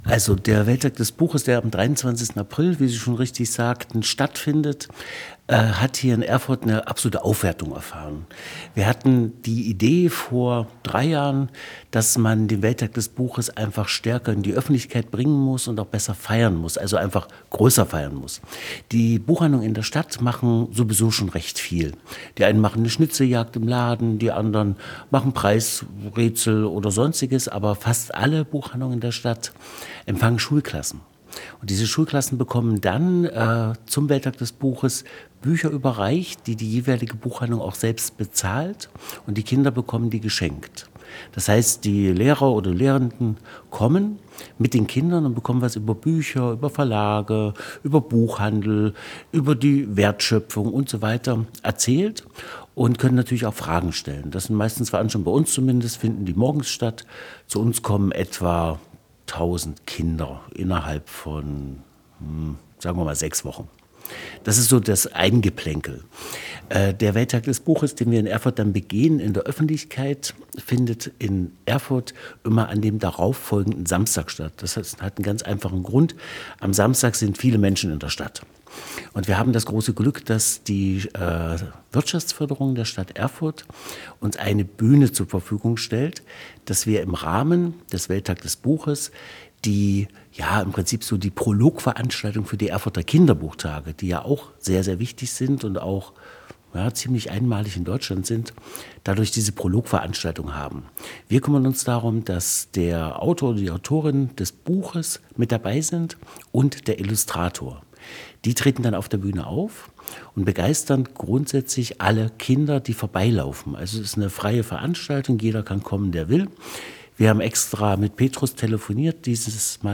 Daraus hat sich dieses Gespräch ergeben.